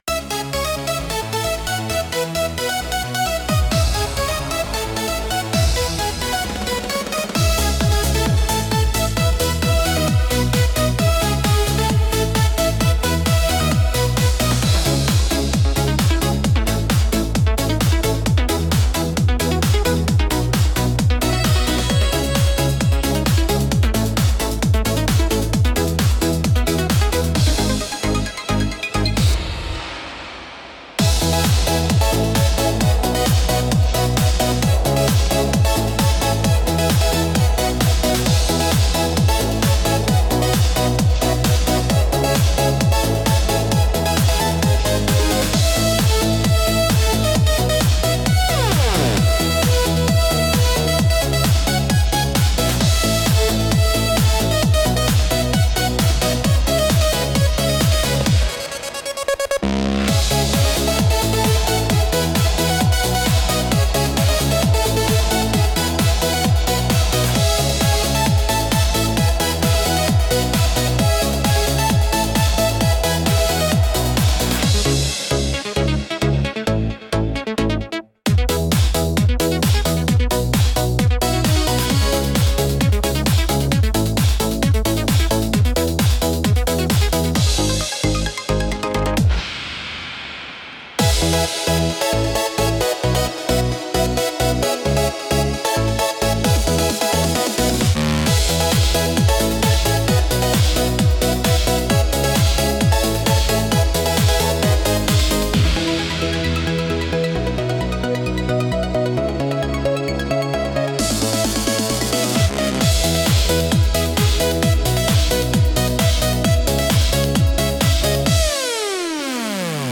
Instrumental - Europhoria 2.32